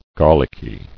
[gar·lick·y]